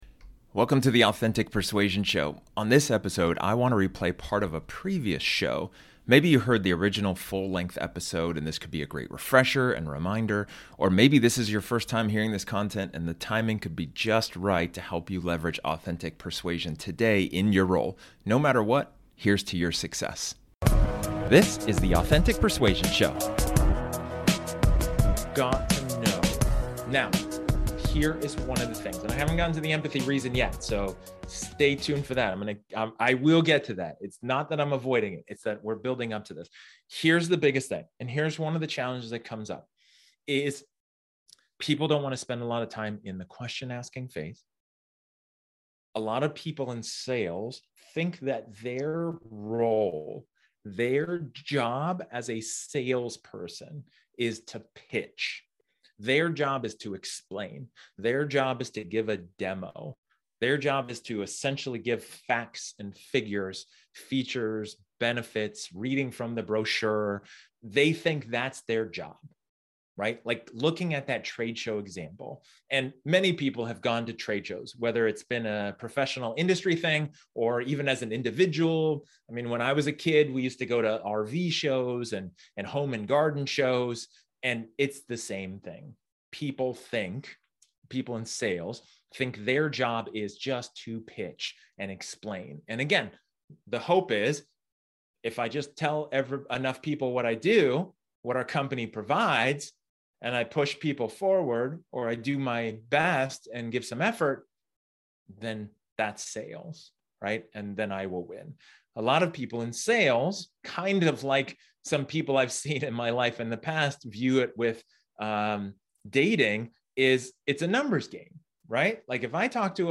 This episode is an excerpt from one of my training sessions where I talk about effective empathy.